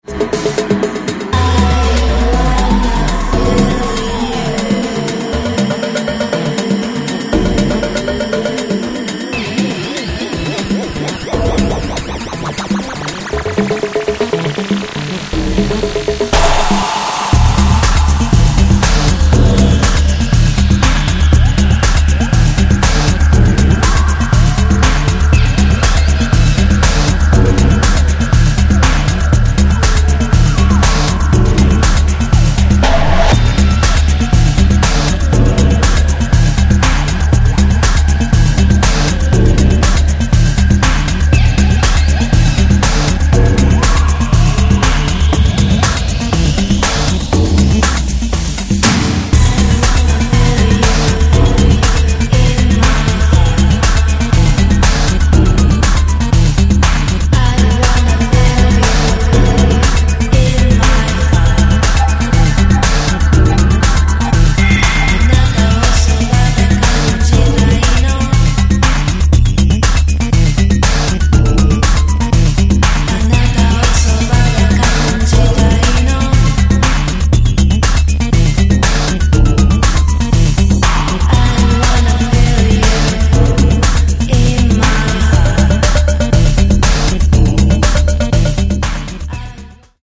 ニューウェイヴ/EBM/アシッド・ハウス/トライバル等の要素を退廃的に昇華した、濃厚なデビュー作となっています。